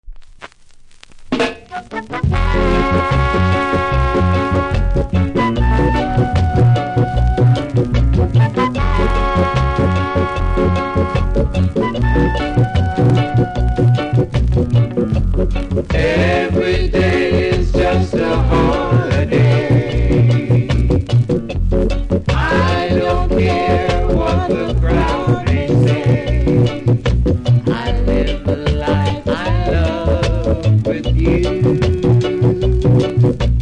盤に少し歪み、曇り、センターずれありますがプレイは問題無いレベル。
キズも少なめノイズもなく良好なので試聴で下さい。